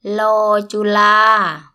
– loor _ ju – laa
loor-ju-laa.mp3